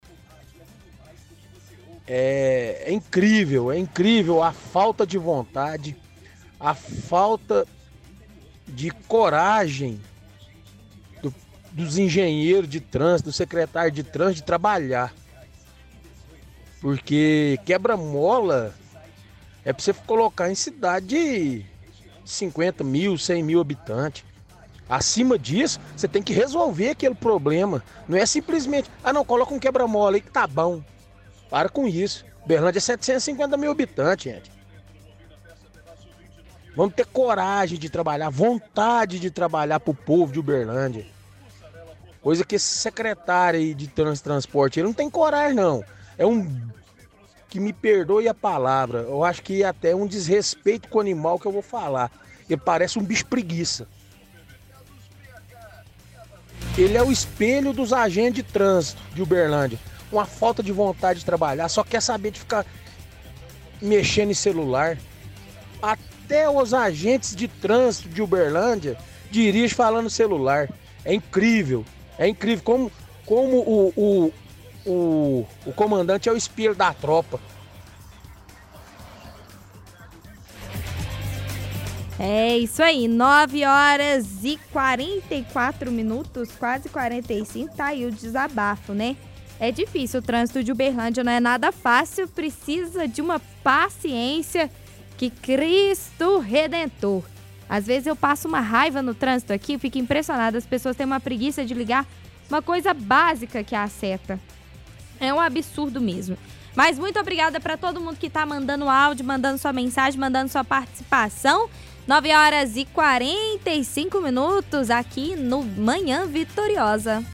– Ouvinte reclama da quantidade de quebra-molas em Uberlândia.